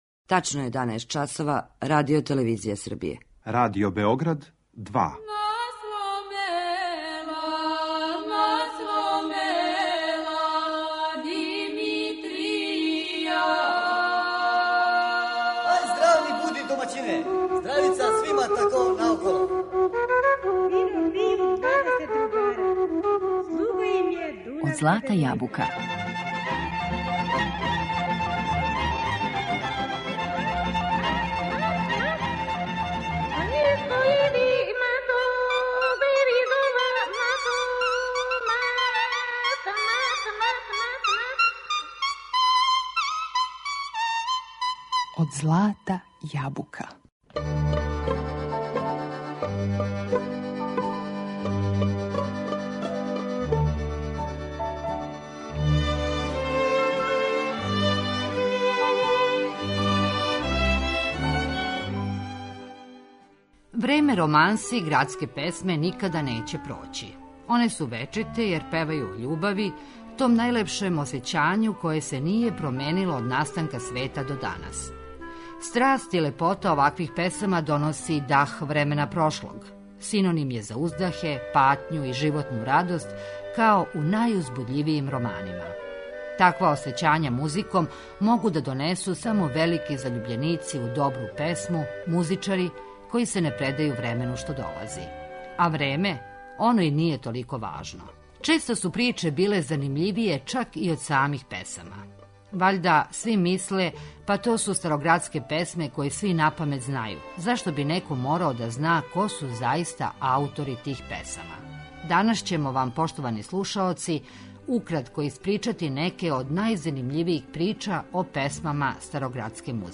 Романсе и градске песме